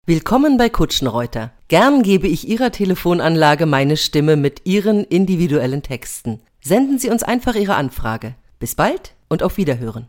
Im Studio werden Ihre Ansagen individuell für Sie produziert – KI-generierte Ansagen oder Texte ‚von der Stange‘ gibt’s bei uns nicht.